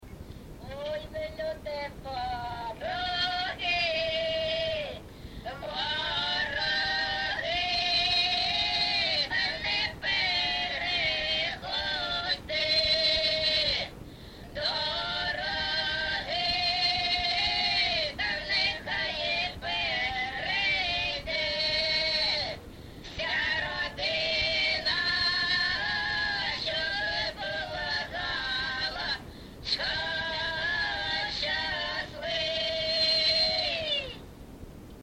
ЖанрВесільні
Місце записус. Некременне, Олександрівський (Краматорський) район, Донецька обл., Україна, Слобожанщина